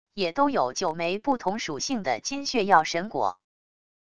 也都有九枚不同属性的金血药神果wav音频生成系统WAV Audio Player